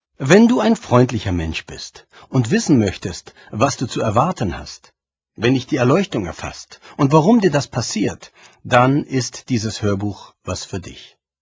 Hörbuch, 68 Minuten
Gelesen von Christian Anders